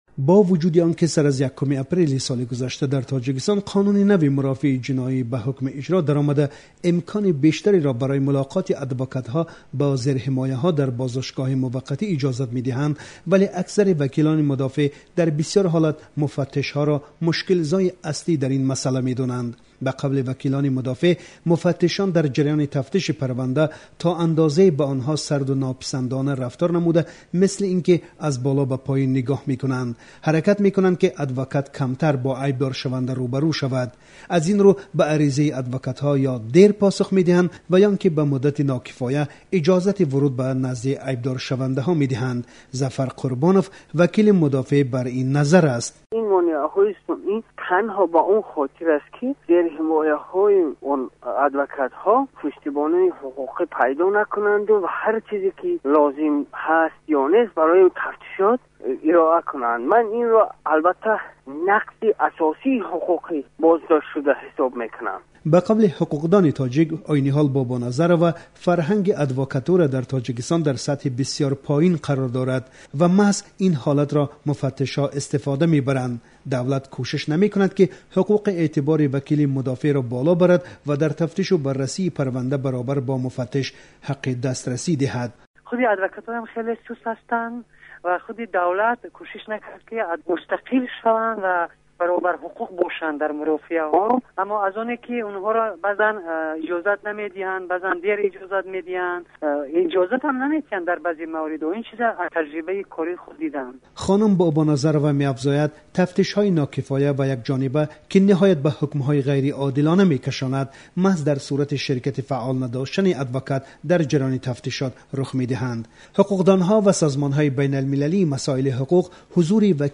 Гузориши